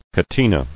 [ca·te·na]